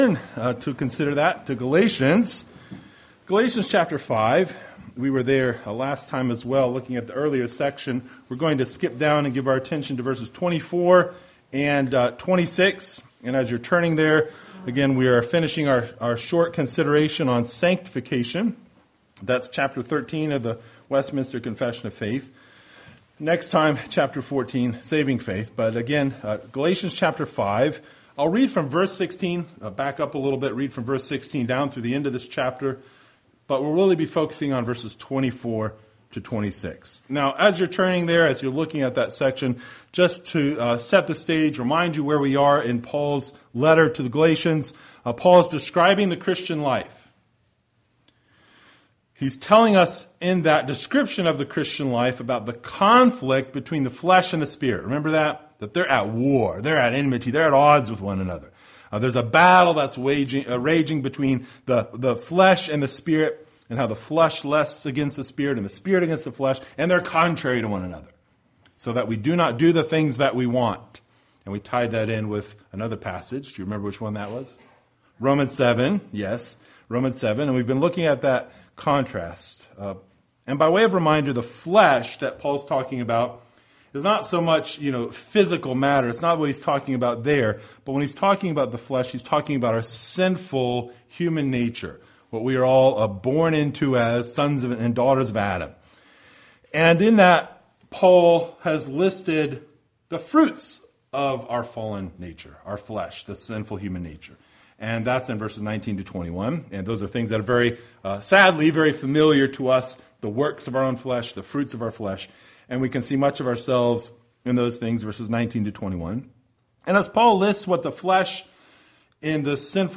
The Westminster Confession of Faith Passage: Galatians 5:24-26 Service Type: Sunday Evening Related « By Faith